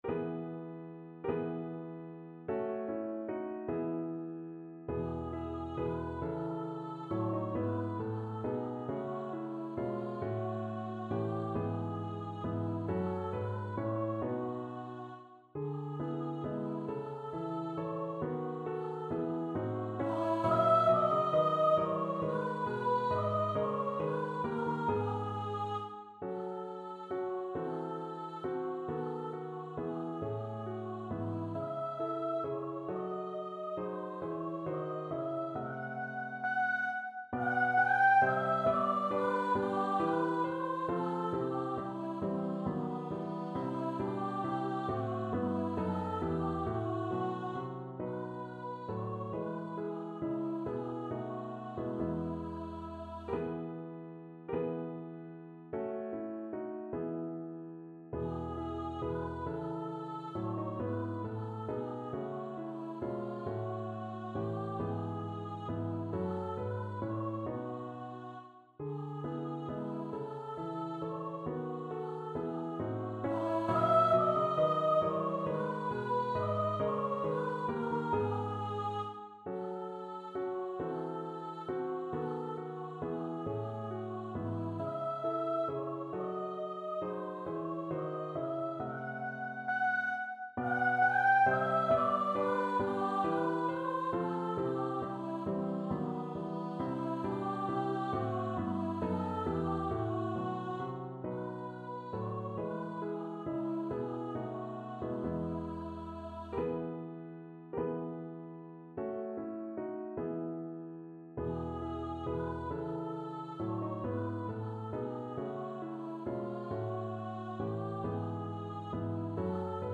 Free Sheet music for Choir (SATB)
3/8 (View more 3/8 Music)
Allegretto (. = 50)
Classical (View more Classical Choir Music)